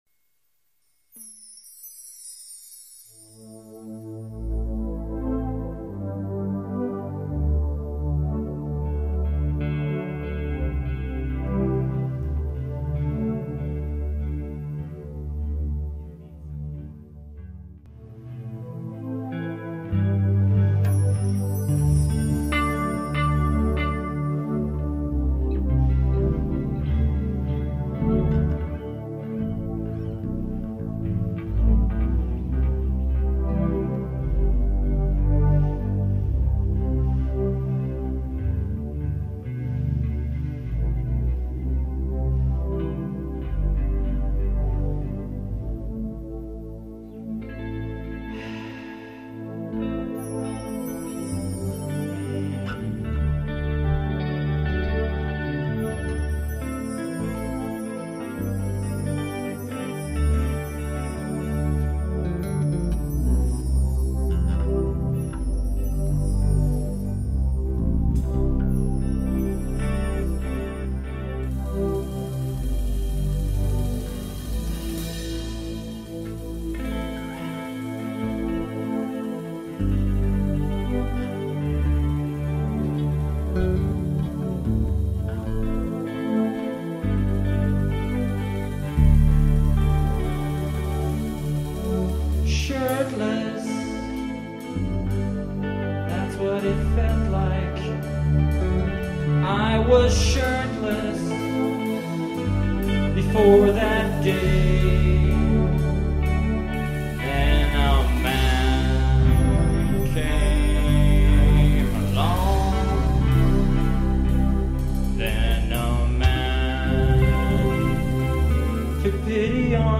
ESPN Here’s the original, un-edited raw version that includes the pre-song banter